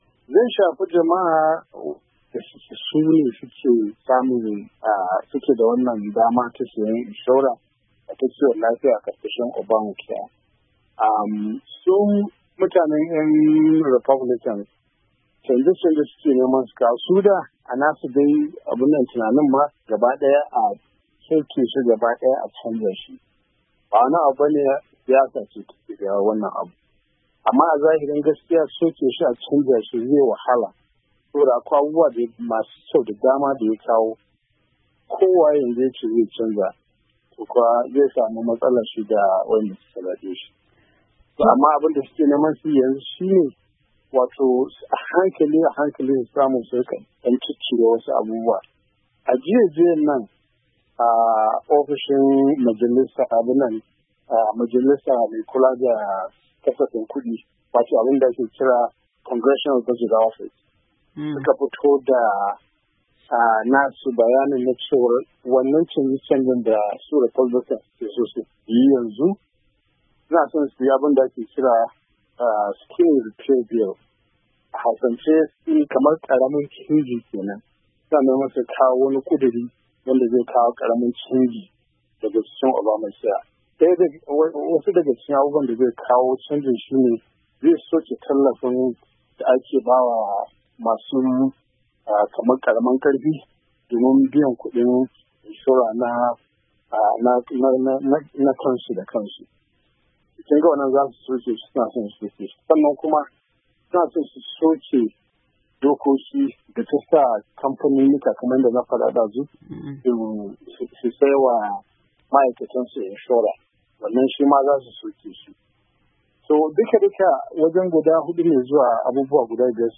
WASHINGTON D.C. —